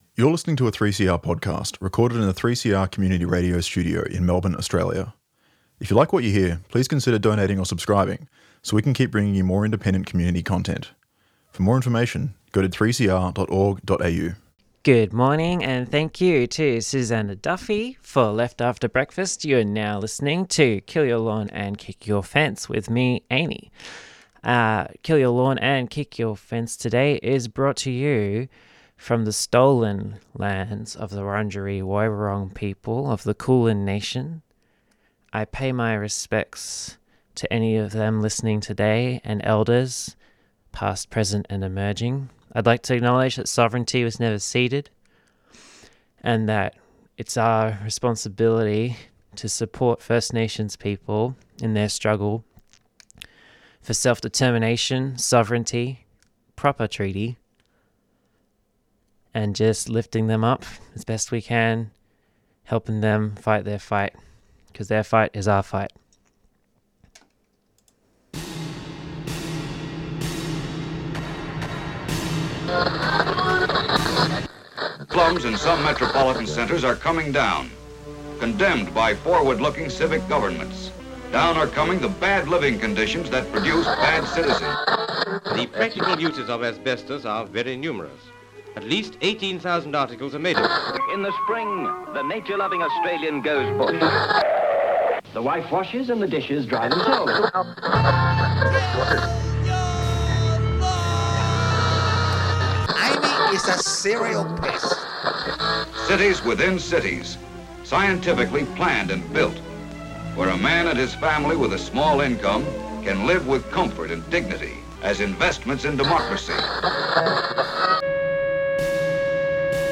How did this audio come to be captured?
We also hear rally vox pops and speeches from the Neighbourhood Houses rally that was held outside parlimetn on the 18th of Ferbruary.